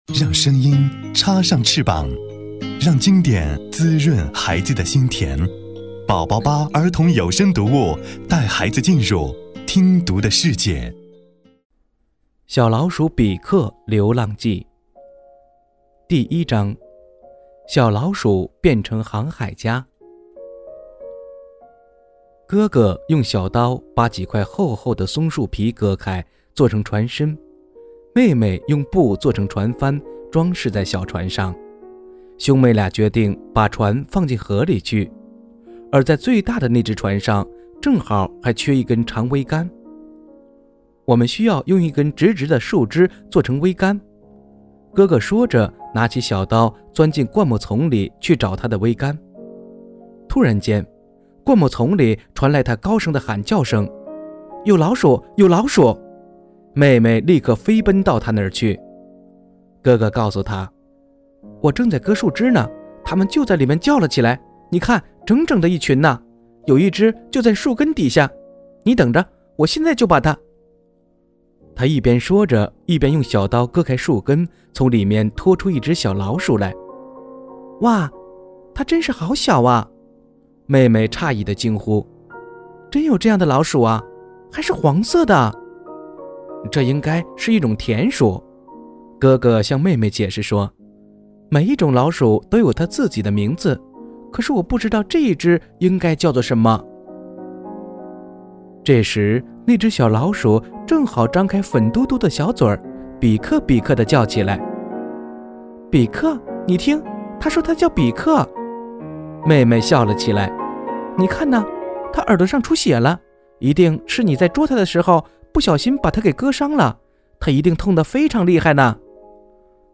首页>mp3 > 儿童故事 > 01小老鼠变航海家(小老鼠比克流浪记)